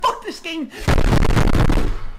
fuck-this-game-banging